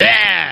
Line of Krunch in Diddy Kong Racing.